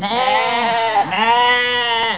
Pecore
pecore.wav